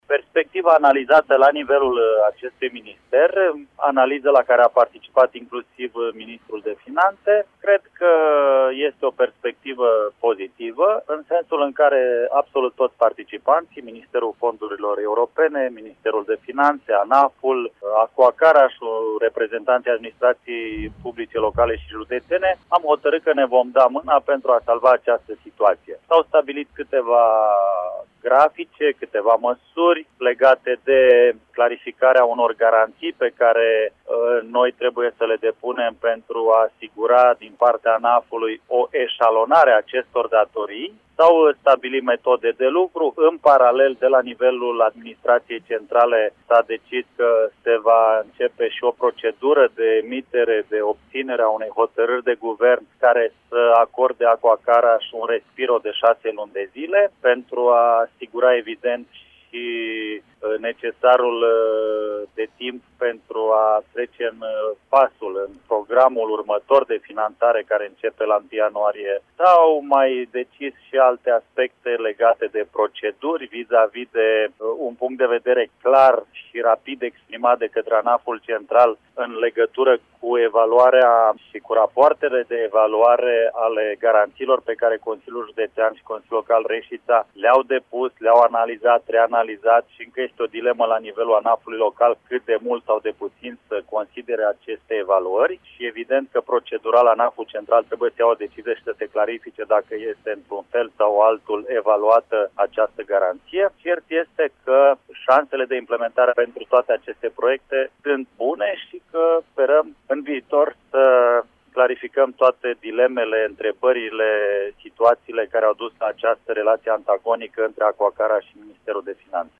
Ascultaţi declaraţia primarului Marcel Vela:
Primar-Caransebes-Marcel-Vela.mp3